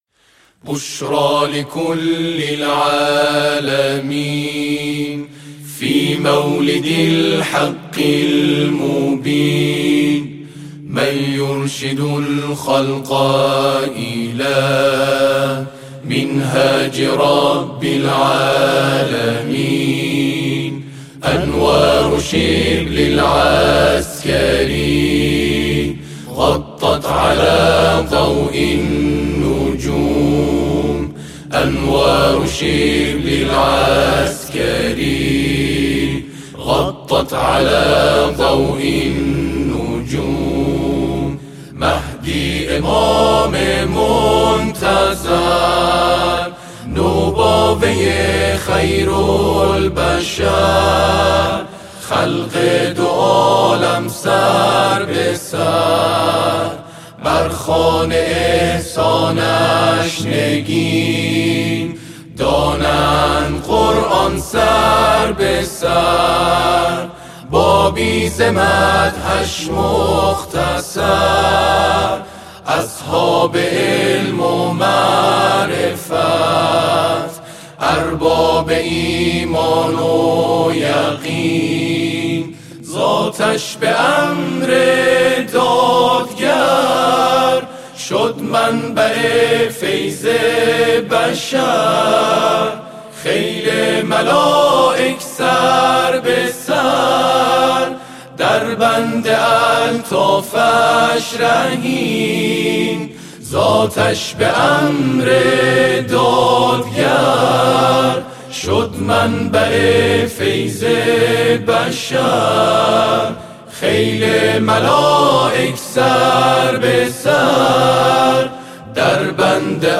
براساس فرم موسیقی آکاپلا